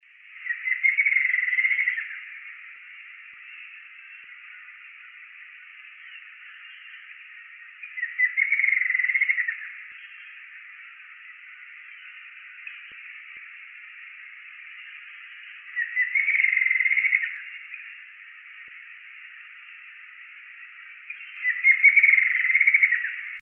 Rufous-margined Antwren (Herpsilochmus rufimarginatus)
Life Stage: Adult
Province / Department: Misiones
Location or protected area: Reserva Privada y Ecolodge Surucuá
Condition: Wild
Certainty: Recorded vocal
TILUCHI-ALA-ROJIZA.MP3